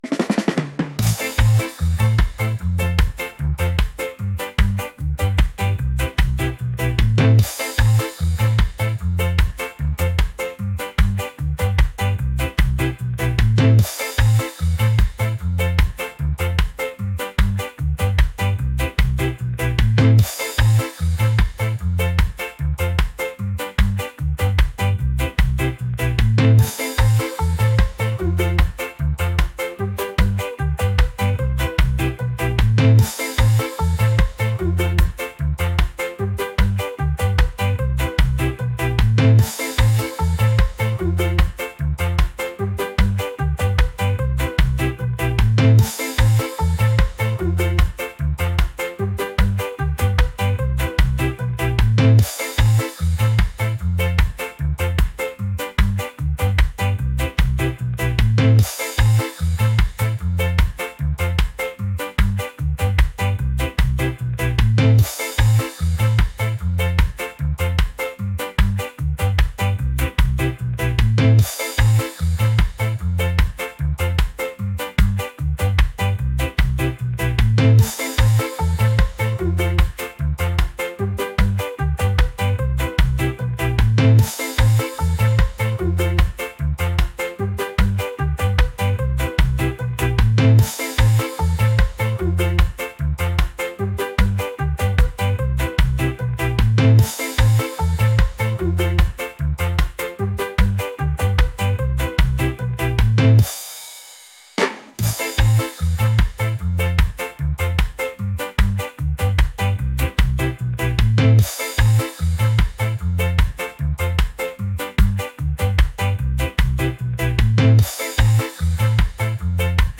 reggae | energetic